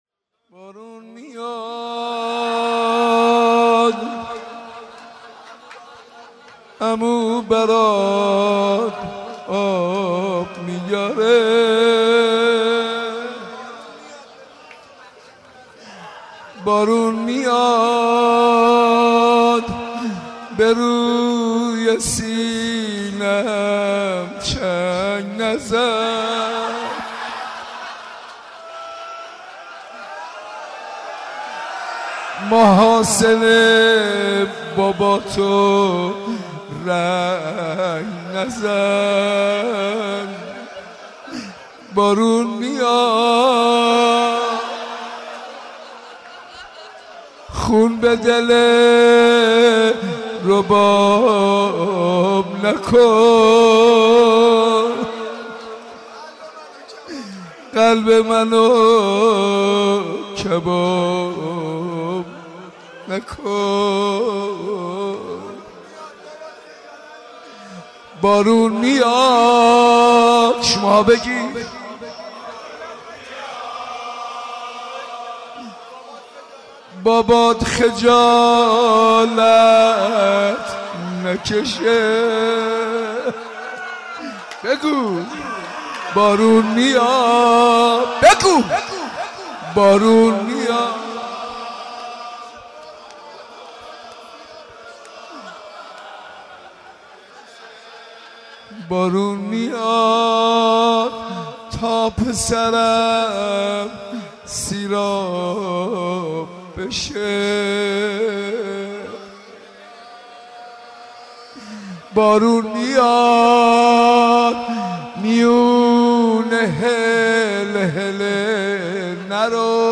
روضه شب هفتم (دهه سوم)؛ حاج منصور ارضی
دریافت 0 bytes گروه دین مشرق- مراسم شب هفتم(دهه سوم) مصادف با شب بیست و هفتم محرم الحرام، با حضور پرشور عزاداران حضرت سیدالشهدا(ع) در حسینیه بیت الزهرا(س) برگزار گردید .